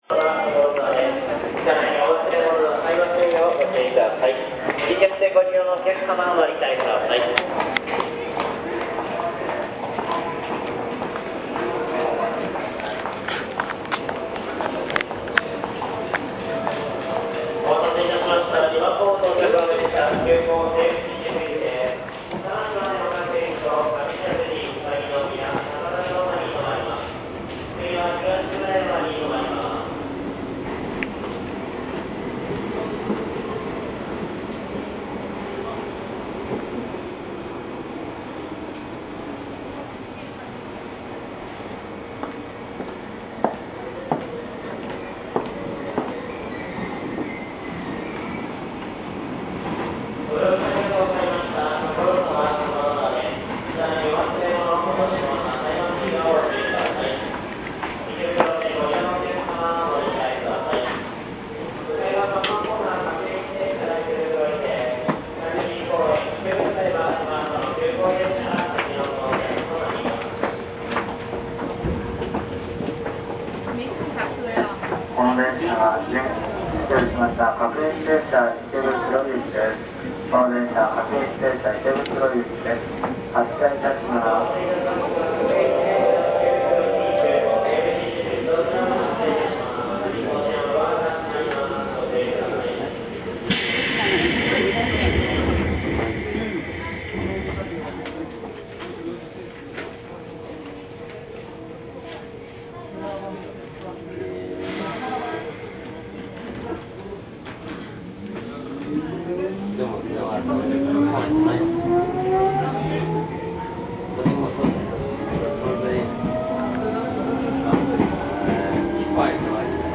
音声2　3000系の走行音